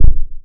Isoulated/Assets/Audio/Effects/Step1FX.wav at 001bb14f165fd9b897952c969b0b6c20730ed8b5
Step1FX.wav